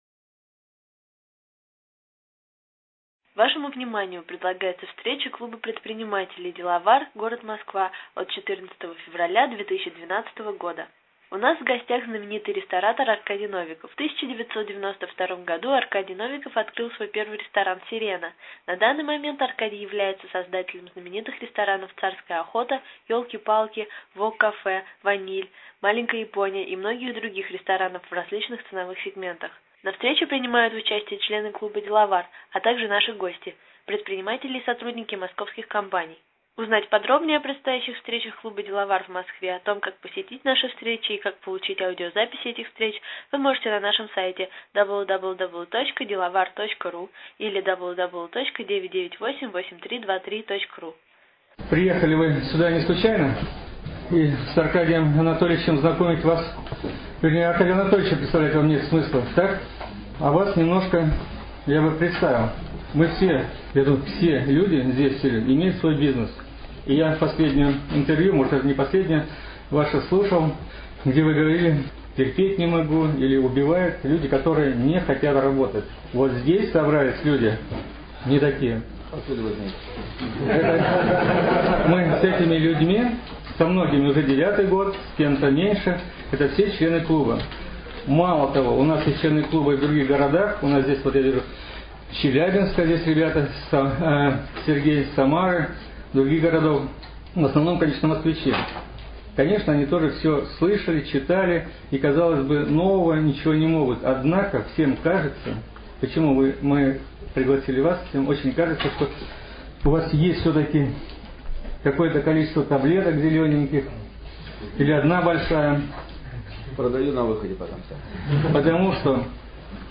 14 февраля 2012 года состоялась очередная встреча МКП Деловар.
У нас в гостях был знаменитый ресторатор Аркадий Новиков.